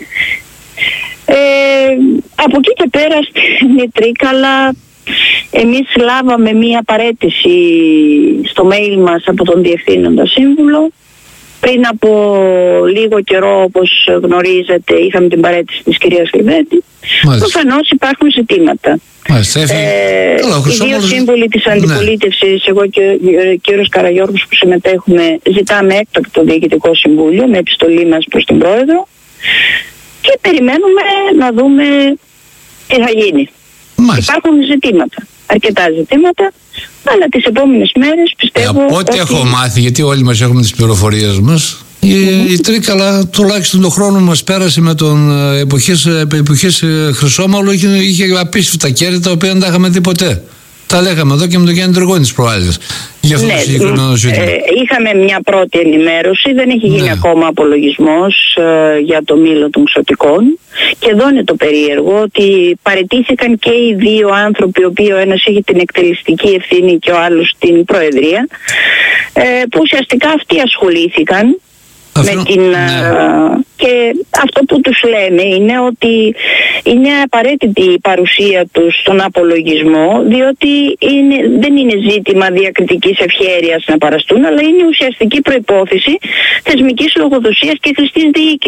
Η είδηση που κυκλοφορούσε ως φήμη μέρες τώρα, έγινε γνωστή και από τη δημοτική σύμβουλο Λώρεν Κασσοπούλου στην εκπομπή “Χαμηλές Πτήσεις” στο Ράδιο Ζυγός, που μάλιστα έκανε επισήμανε το γεγονός ότι η παράταξη του Ηλιάδη, ζήτησε στον απολογισμό να είναι και οι δύο παρόντες.